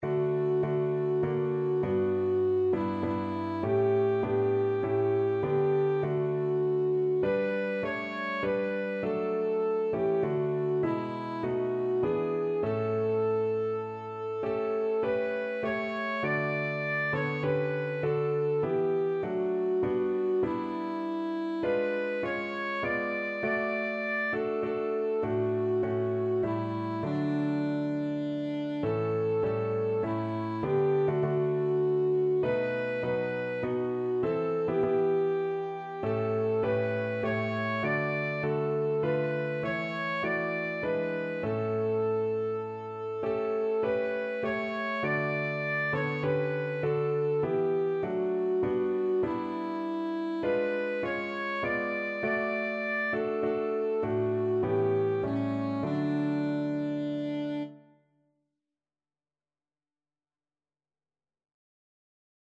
Alto Saxophone
3/4 (View more 3/4 Music)
Classical (View more Classical Saxophone Music)